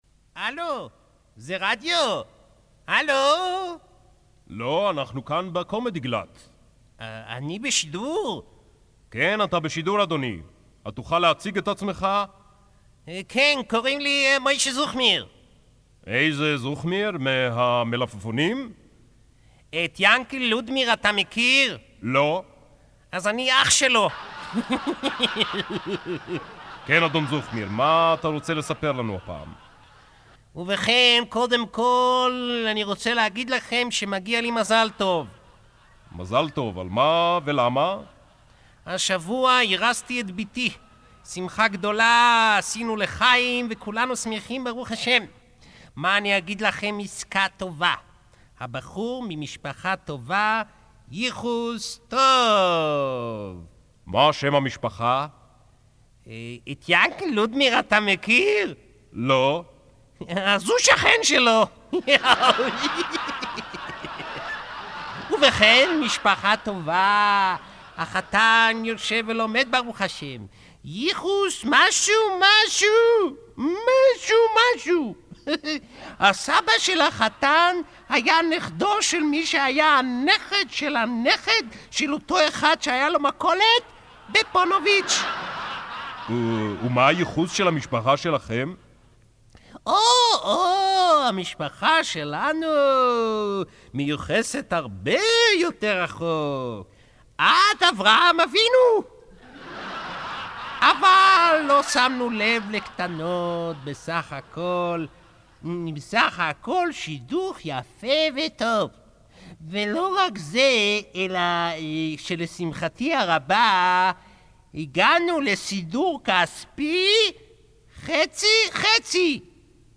קומדיגלט (קלטת ישנה = חיכויים ועוד)